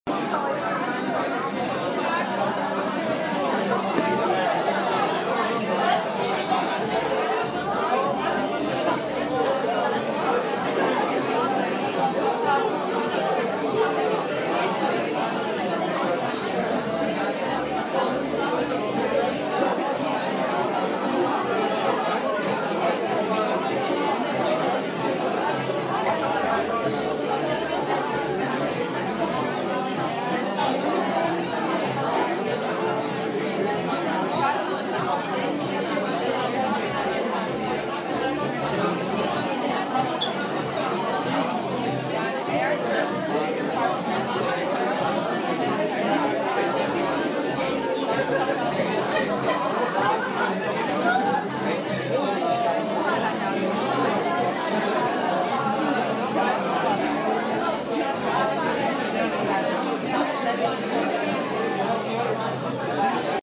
Bar Sound